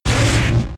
Cri de Sablaireau K.O. dans Pokémon X et Y.